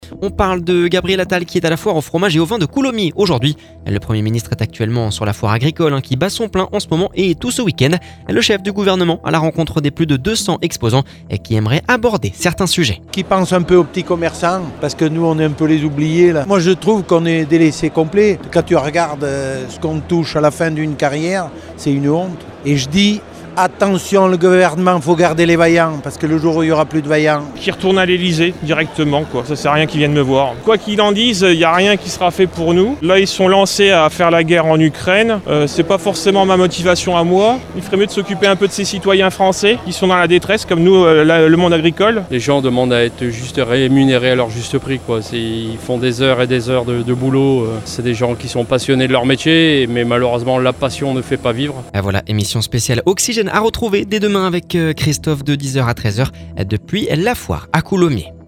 COULOMMIERS - Gabriel Attal à la foire aux fromages et aux vins !